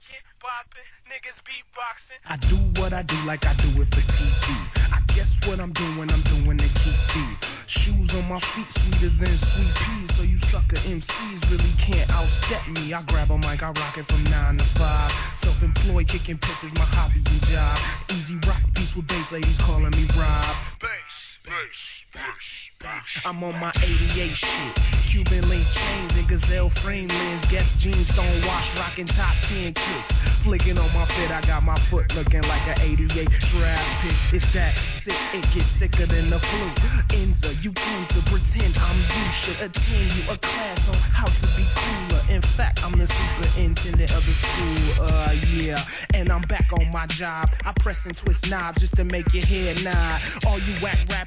HIP HOP/R&B
OLD SCHOOLな軸を持ちつつ新鮮なサウンド・プロダクションで魅了する1stアルバム!!